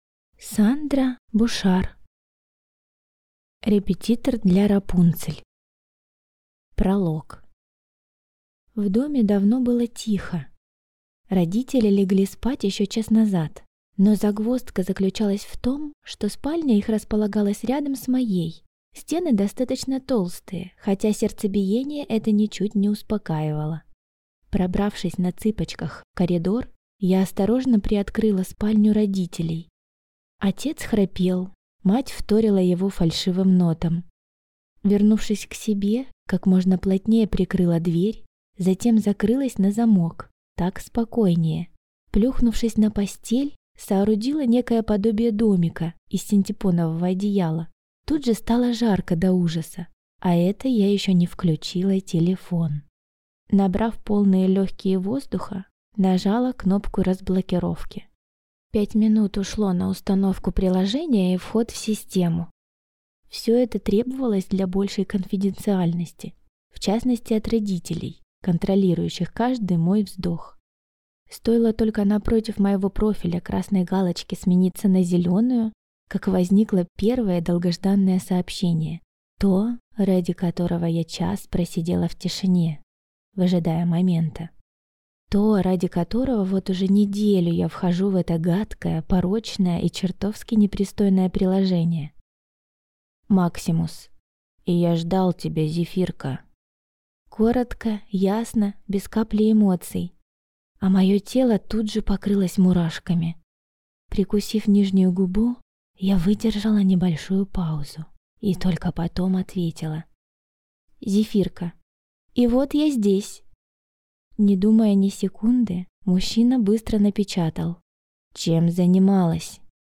Аудиокнига Репетитор для Рапунцель | Библиотека аудиокниг